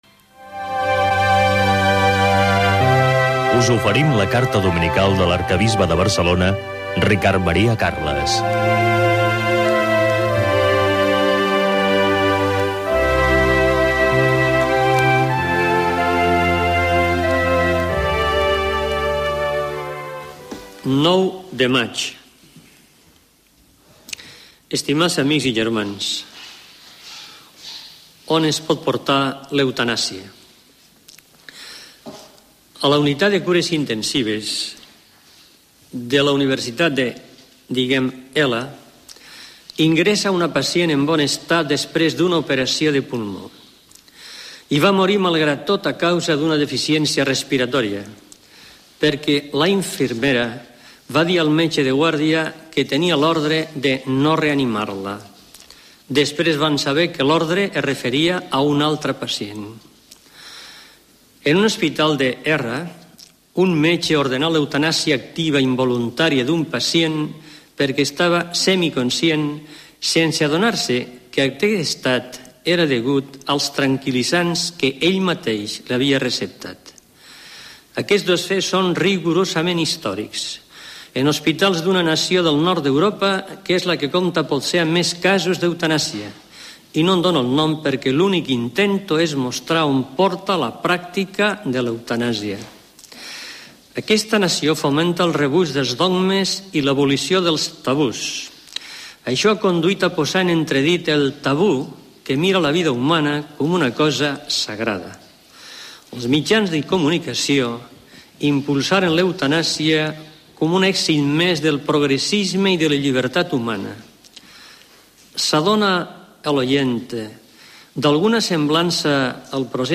Careta del programa, carta del bisbe Ricard Maria Carles, escrita el 9 de maig, sobre l'eutanàsia. Careta del programa.
Religió